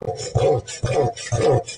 鹤鸵叫声 食火鸟鸣叫声